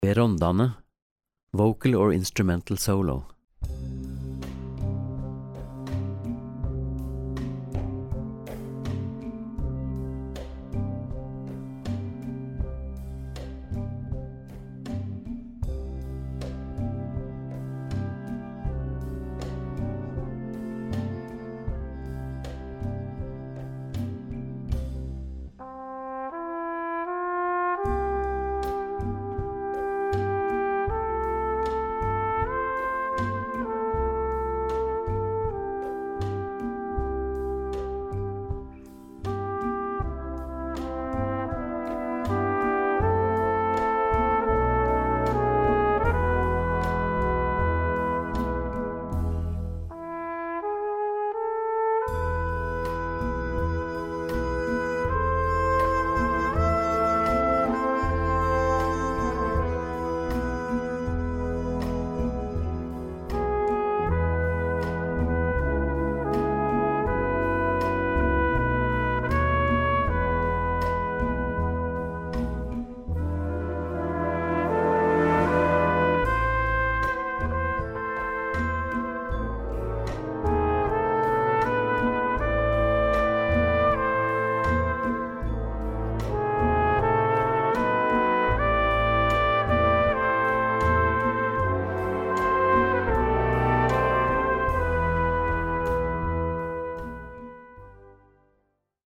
Gattung: für Solo Gesang oder B Instrumente
Besetzung: Blasorchester